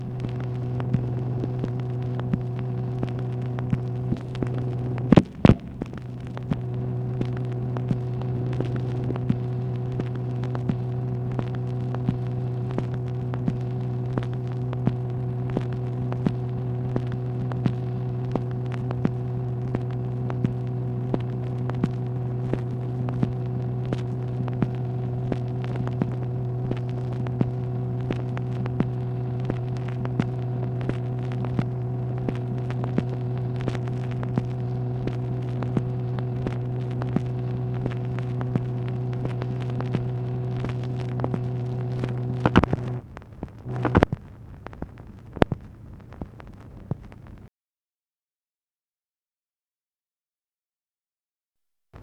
MACHINE NOISE, May 25, 1965
Secret White House Tapes | Lyndon B. Johnson Presidency